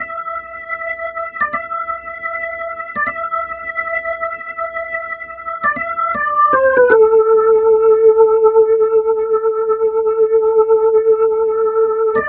Track 07 - Organ 02.wav